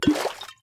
sounds / item / bottle / fill1.ogg